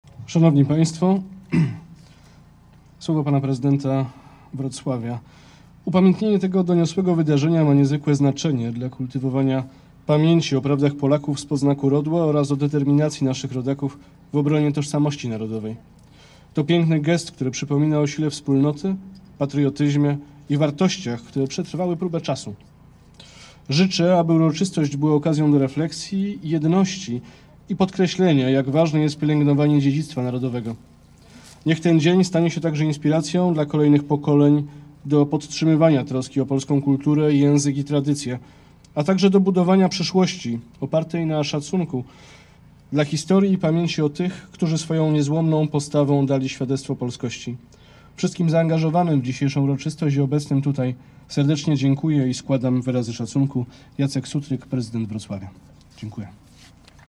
Uroczystości miały miejsce przy kościele św. Marcina na Ostrowie Tumskim we Wrocławiu.
W czasie uroczystości głos zabrali: